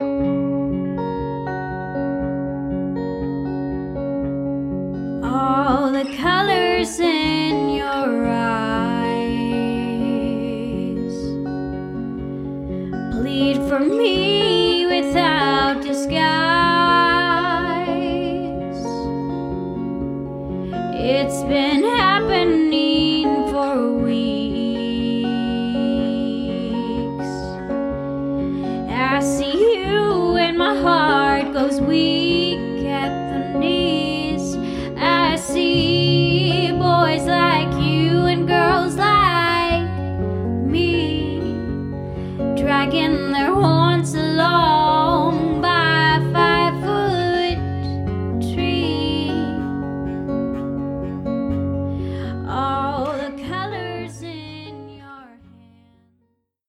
Guitar, Tracking, Mixing
guitar